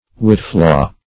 Search Result for " whitflaw" : The Collaborative International Dictionary of English v.0.48: Whitflaw \Whit"flaw`\, n. [See Whitlow .]